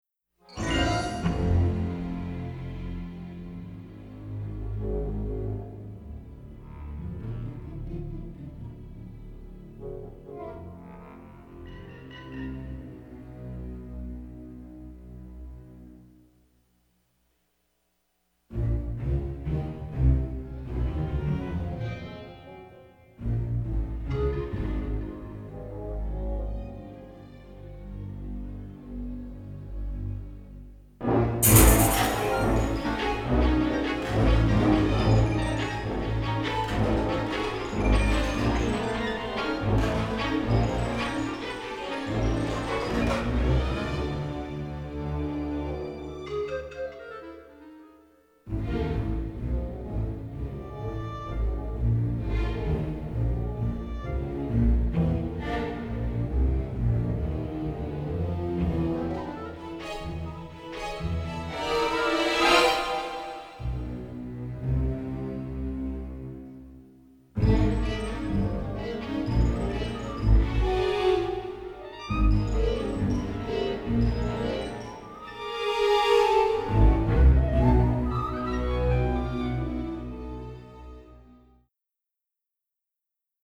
generous string section plus brass ensemble
Striking intense material enters into tonal world